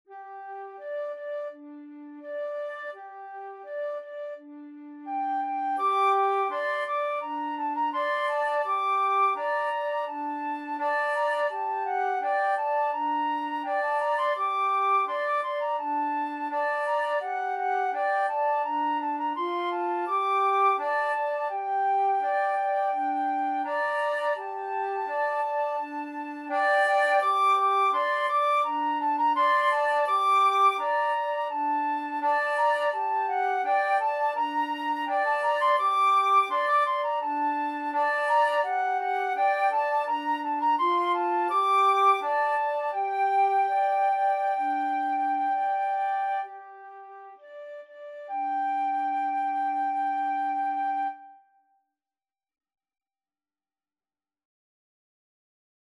Free Sheet music for Flute Duet
G minor (Sounding Pitch) (View more G minor Music for Flute Duet )
Steady two in a bar ( = c. 84)
Traditional (View more Traditional Flute Duet Music)